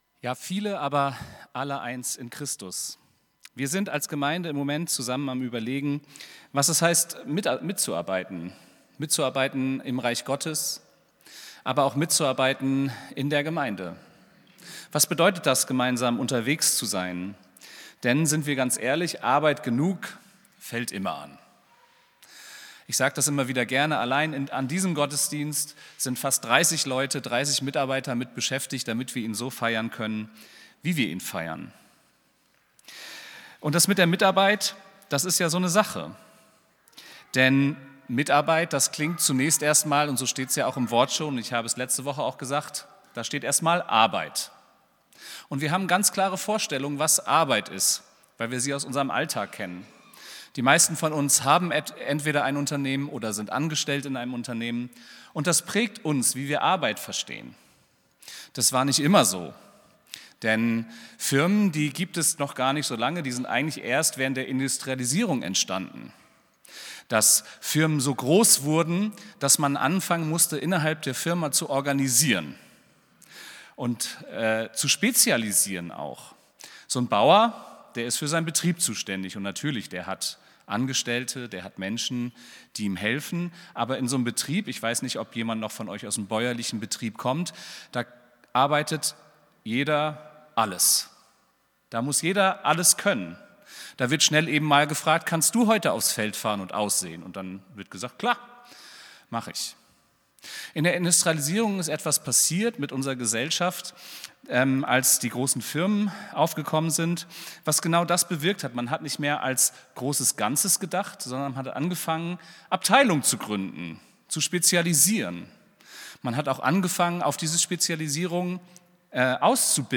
Predigt vom 26.04.2026